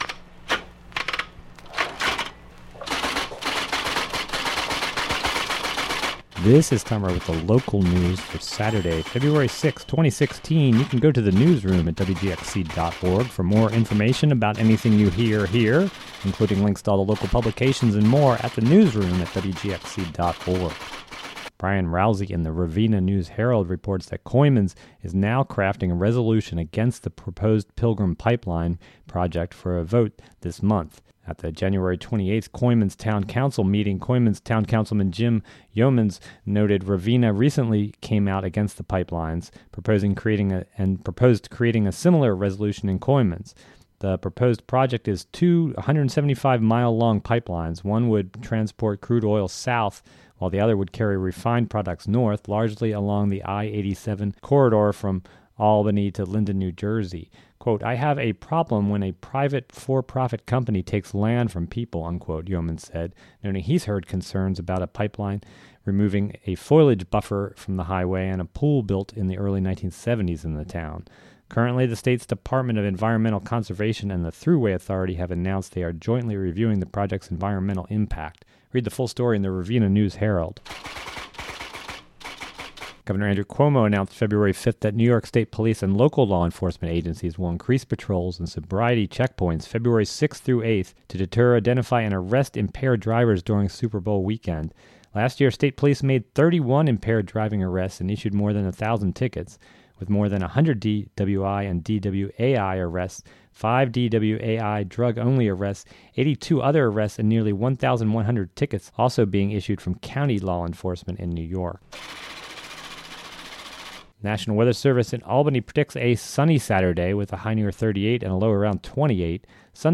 WGXC local news audio link Feb 06, 2016 7:00 am DOWNLOAD or play the audio version of the local headlines and weather for Sat., Feb. 6 (2:35).